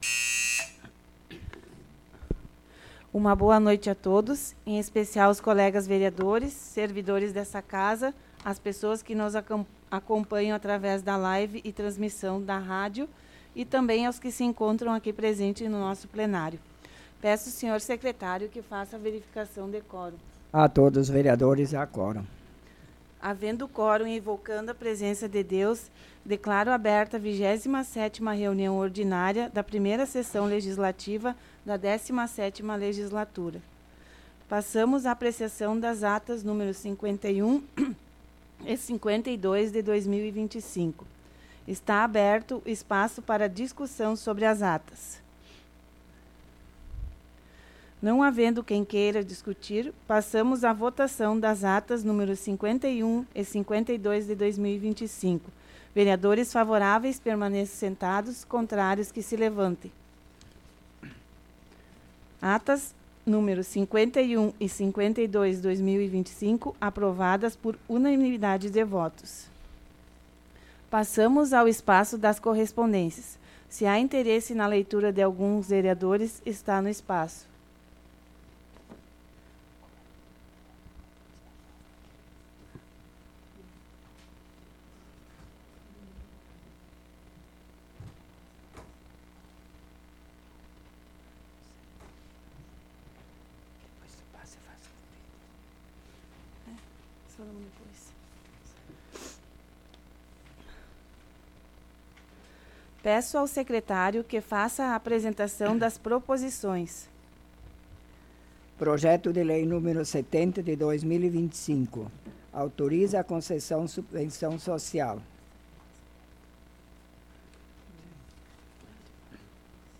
Resumo (27ª Ordinária da 17ª Legislatura)